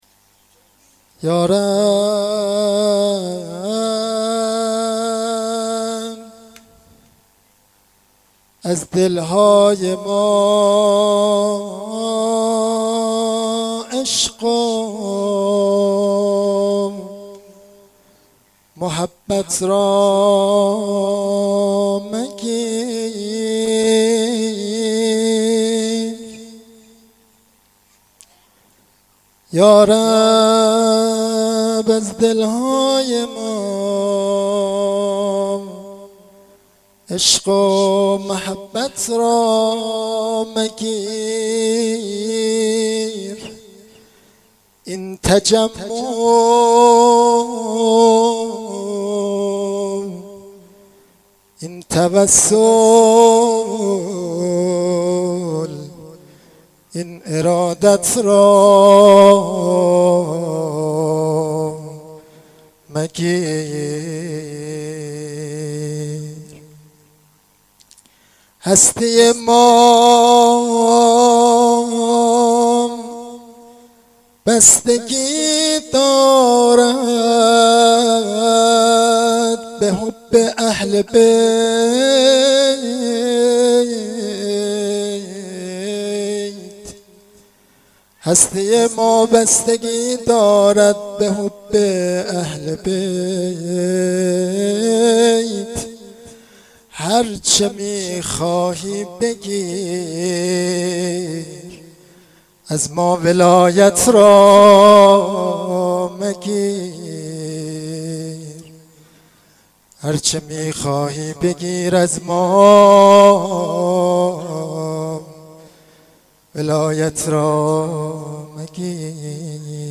روضه شب هفتم محرم 93
Rozeh-Shabe-07-moharram93.mp3